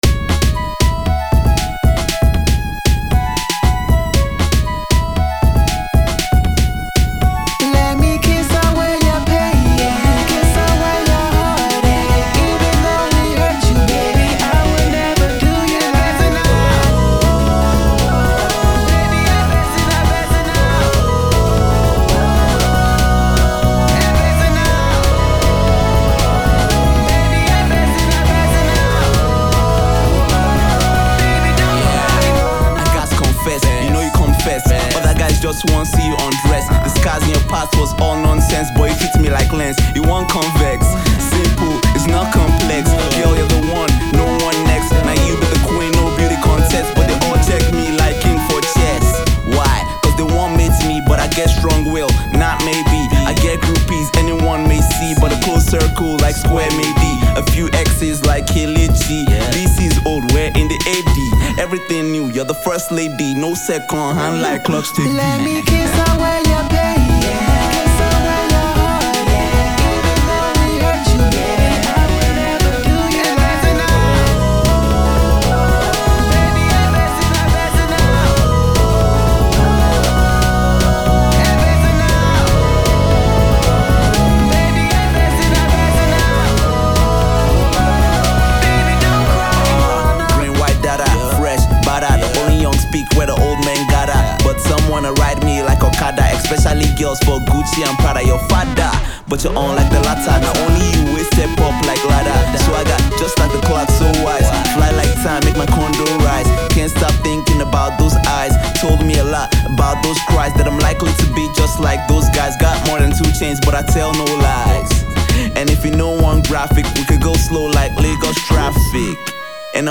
catchy
croons his heart out
uses witty punch lines to do the same